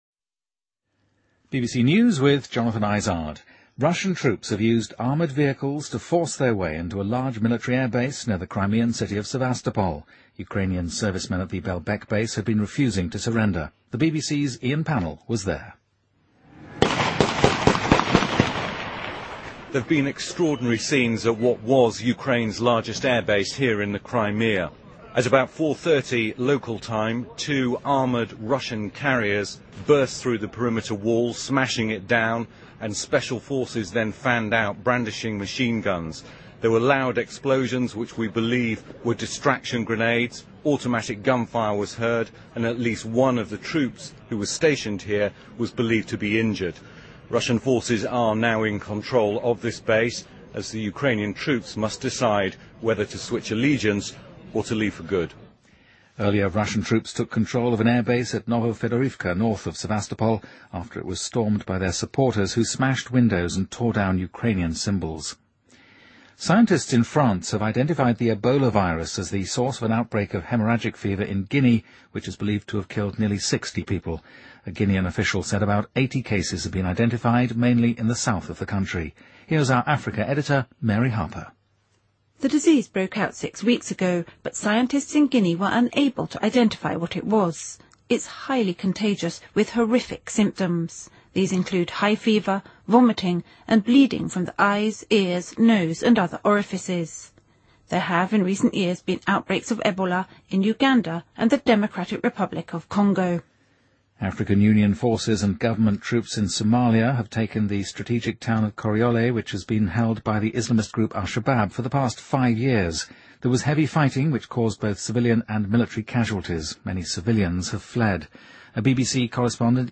BBC news,2014-03-23